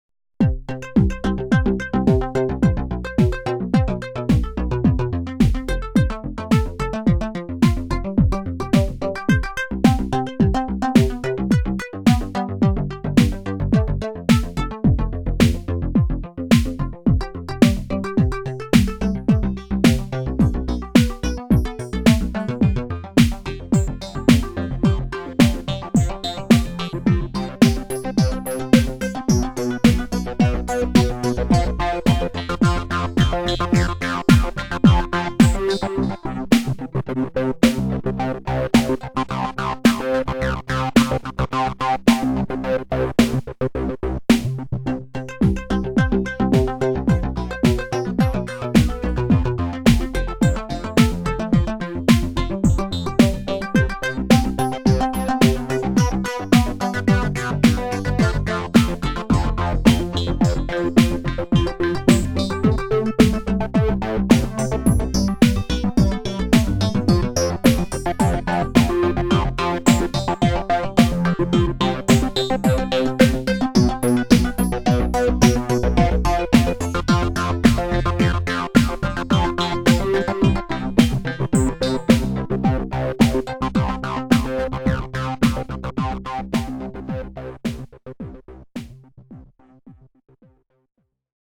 algorythms - rythmical arpeggios from mathematical algorithms
Sound Demos of synthesized music
syntheticarpeggios.wma